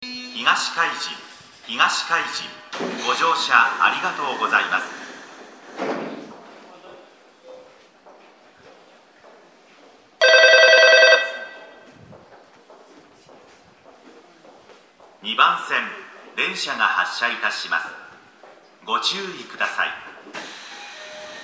駅放送
到着発車 ベルの音が大きすぎる 接近放送のタイミングは相変わらず遅いです。